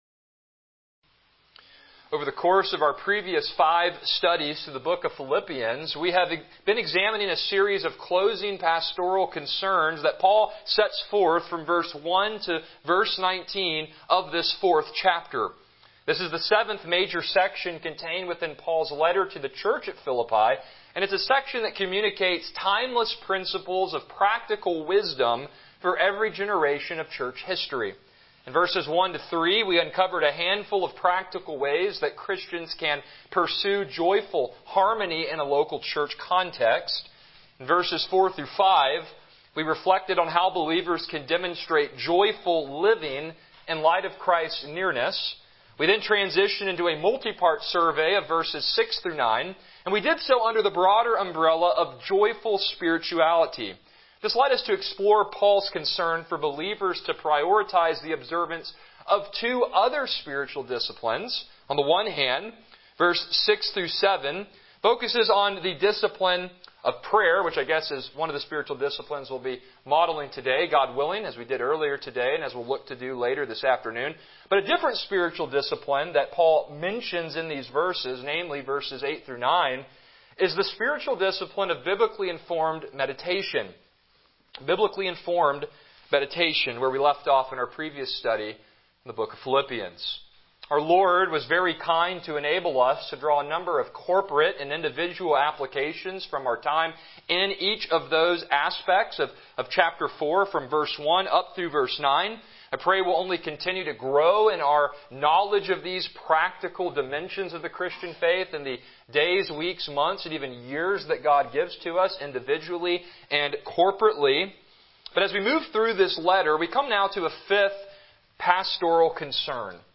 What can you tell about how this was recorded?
Passage: Philippians 4:10, 14-19 Service Type: Morning Worship